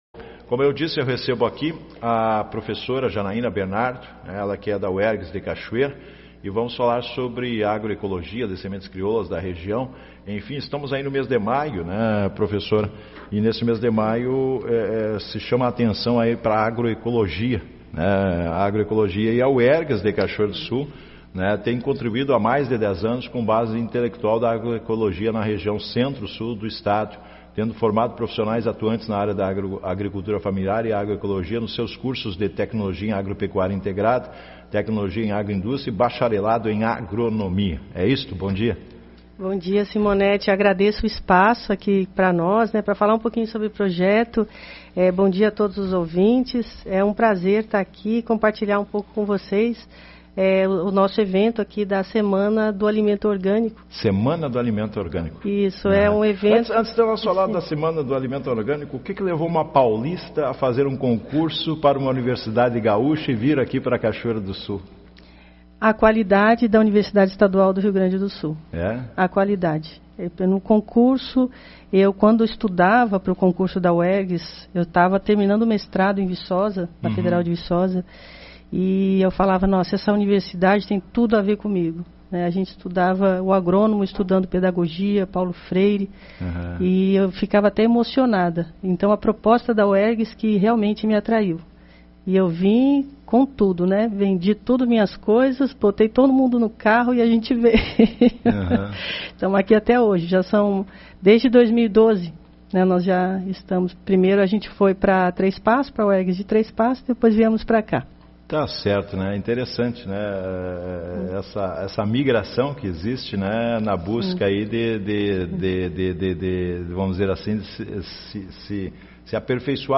Matérias veiculadas em rádios no mês de maio de 2021.